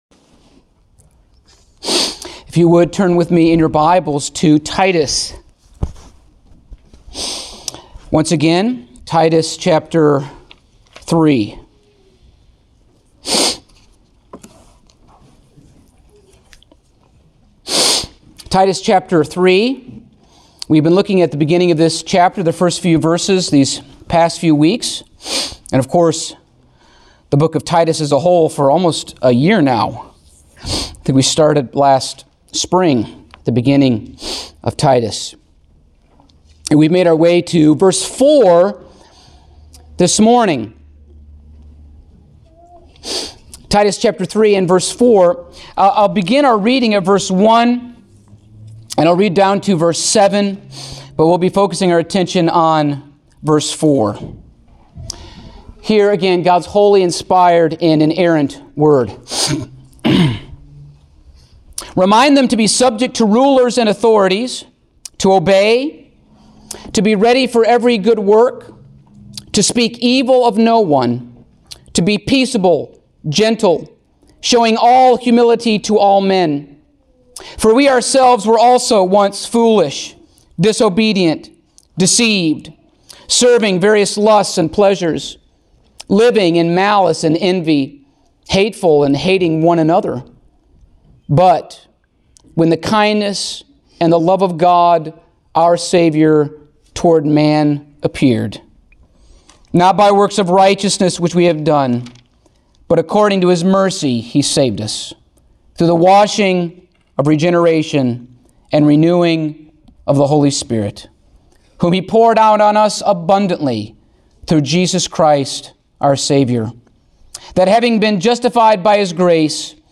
Passage: Titus 3:4 Service Type: Sunday Morning